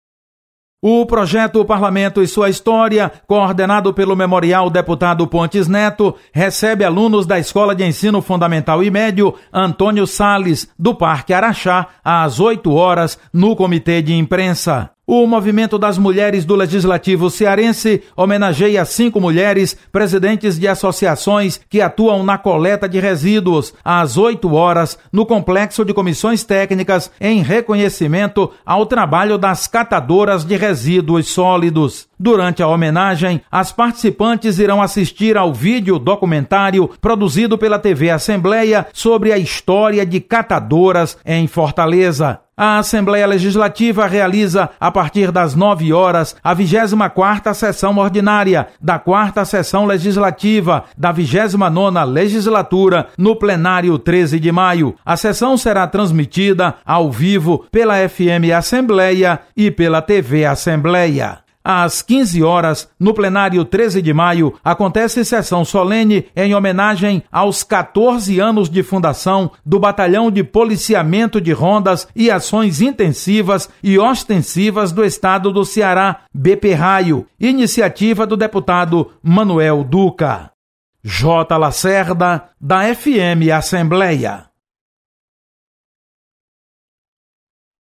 Acompanhe as atividades desta quarta-feira na Assembleia Legislativa. Repórter